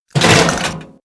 CHQ_GOON_hunker_down.ogg